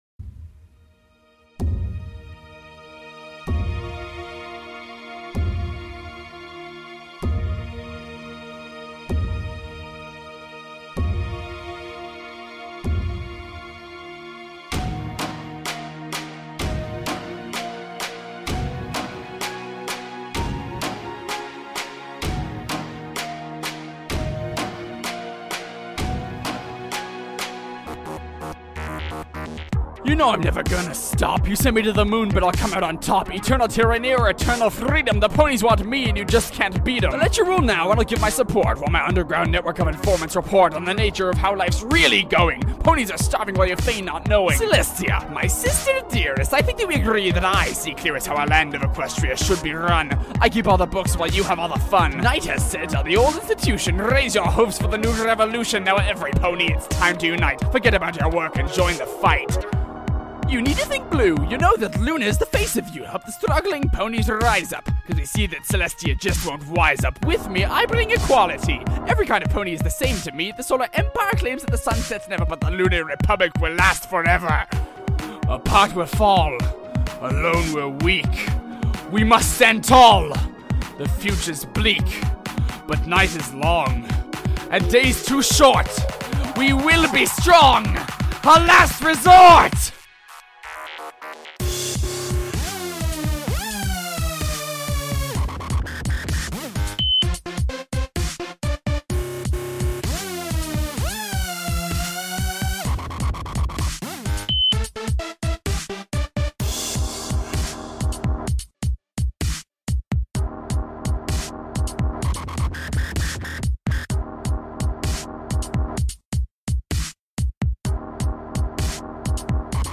Also, slurring syllables together!